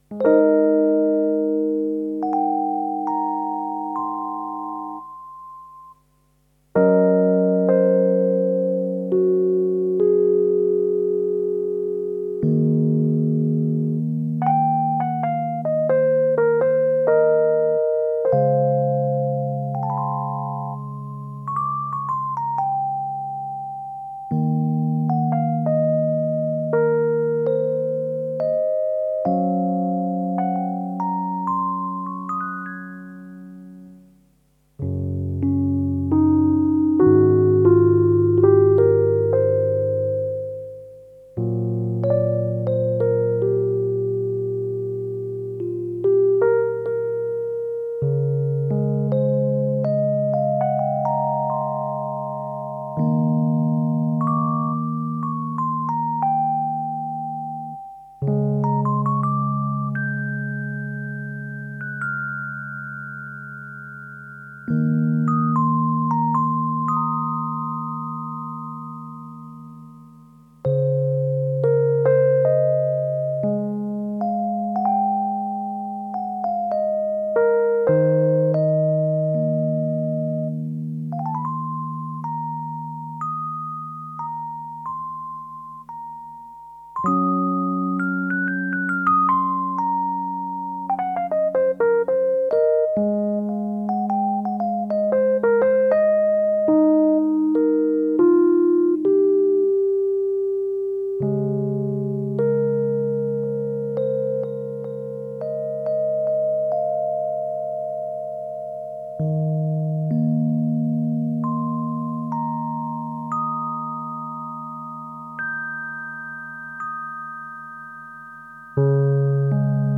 Gemafreie Musik!